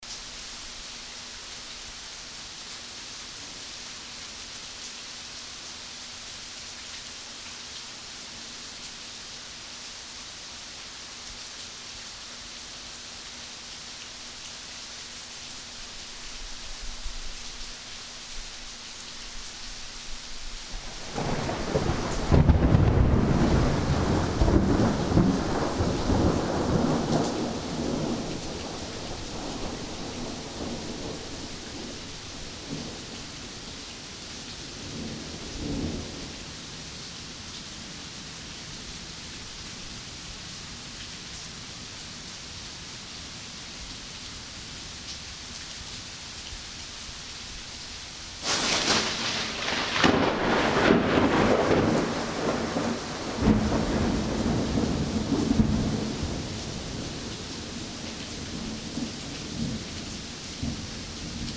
Thunder
big-thunder.mp3